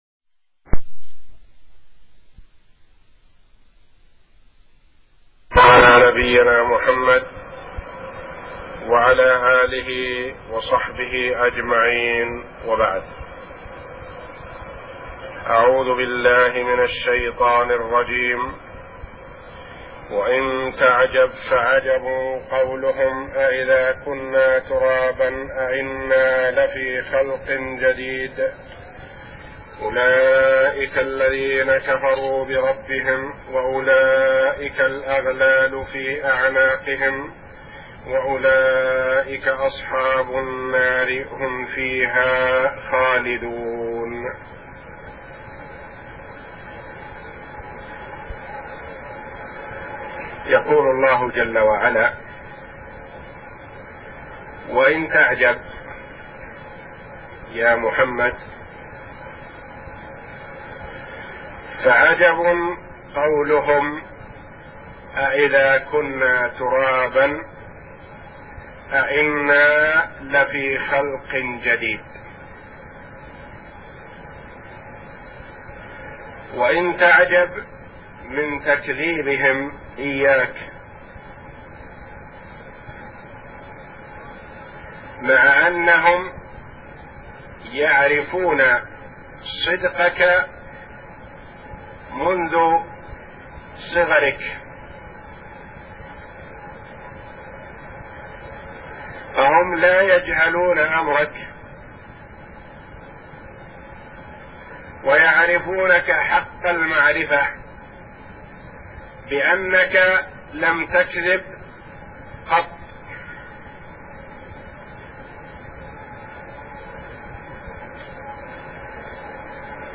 من دروس الحرم المكى الشريف